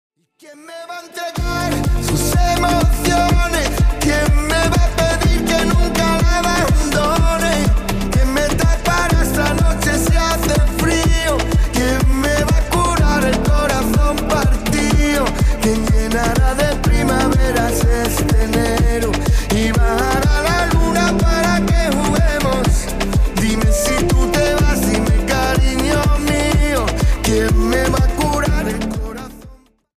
Latina